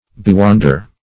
Search Result for " bewonder" : The Collaborative International Dictionary of English v.0.48: Bewonder \Be*won"der\, v. t. [imp. & p. p. Bewondered .] 1. To fill with wonder.